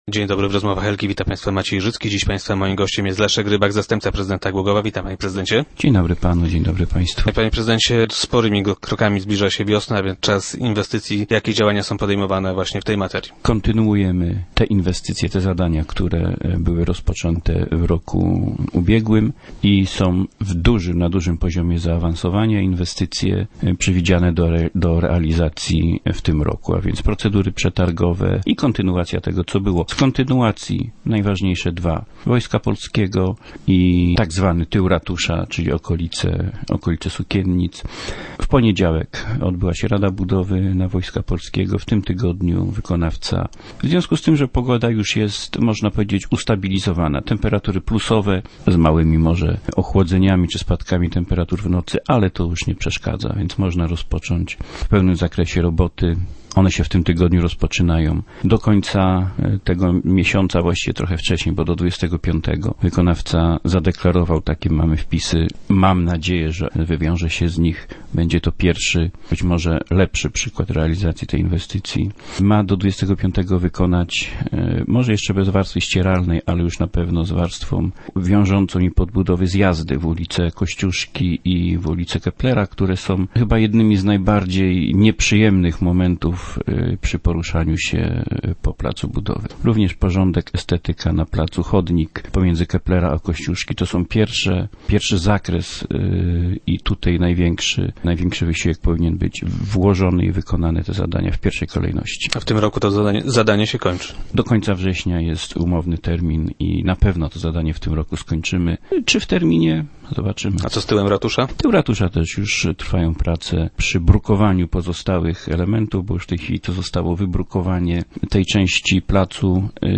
- Takie rozwiązanie dopiero zaczyna być stosowane jako mechanizm przeprowadzania przetargów przez samorządy. Zobaczymy jak nam to wyjdzie. Z opinii jakie zebraliśmy wynika, że przynosi to oszczędności - powiedział nam Leszek Rybak, który był dziś gościem Rozmów Elki. (mai)